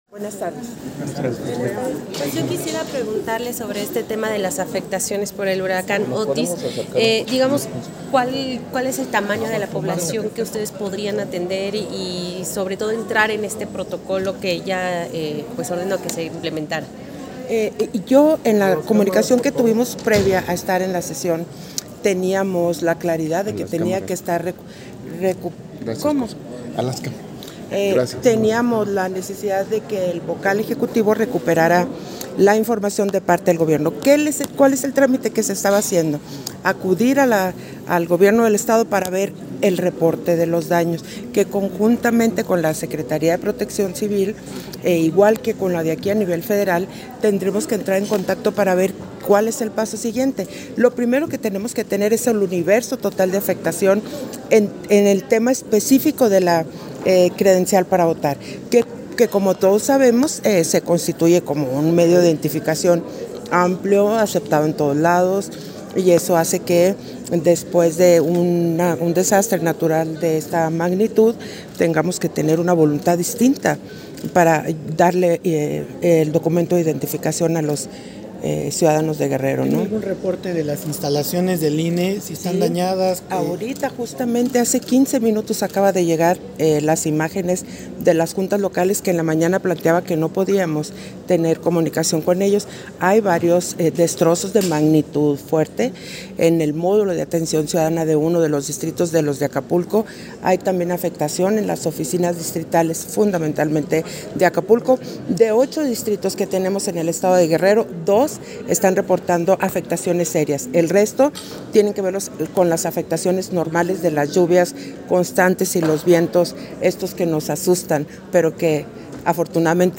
261023_AUDIO_ENTREVISTA-CONSEJERA-PDTA.-TADDEI-SESIÓN-ORD.-1
Entrevista de Guadalupe Taddei, con diversos medios de comunicación, al término de la Sesión Ordinaria del Consejo General